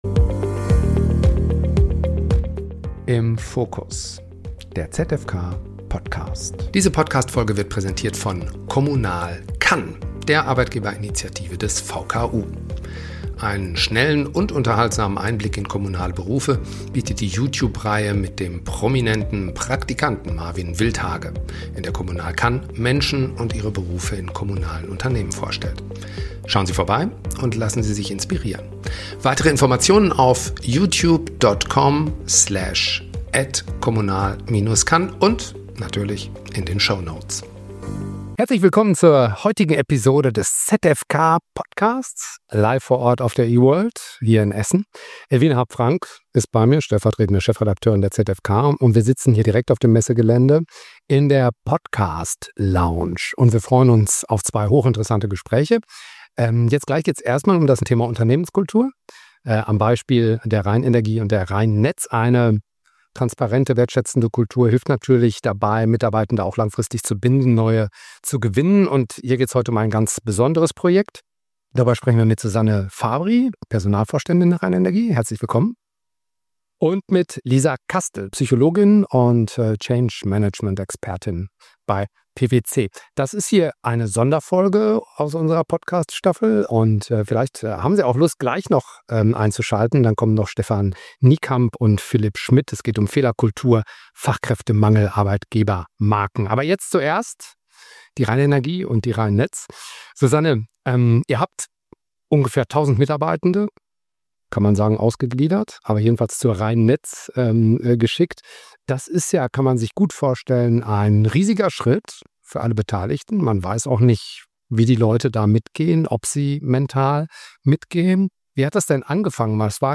Change-Management bei der Rheinenergie - Live auf der E-world – Teil 1 ~ Im Fokus - Der ZFK-Podcast Podcast